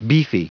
Prononciation du mot beefy en anglais (fichier audio)
Prononciation du mot : beefy